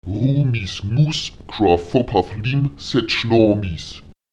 Lautsprecher rúmis’nus kávvopac’lim zejlómis neun Millionen dreihundertsechsundsiebzigtausendachthundertelf